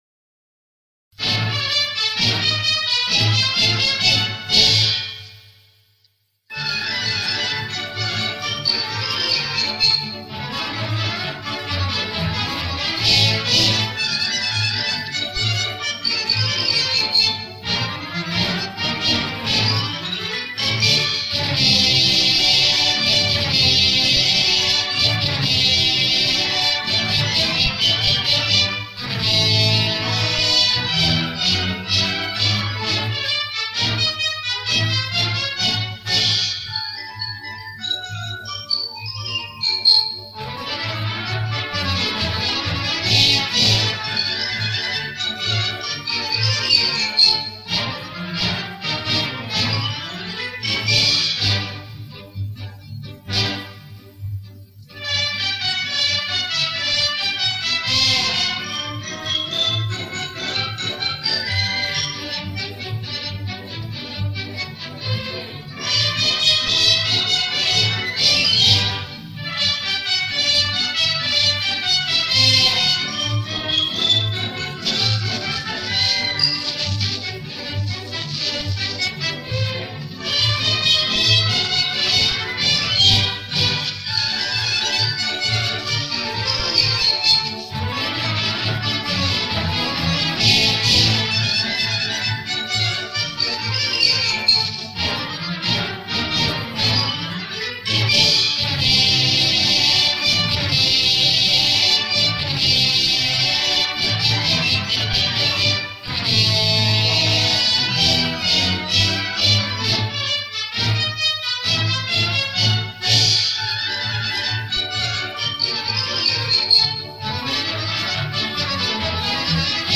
Без применения шумоподавителя.